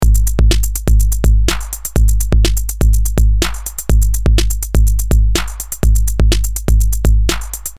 Here I have run the loop through the AR Mk1 overdrive, distorion and both together.
Master distortion: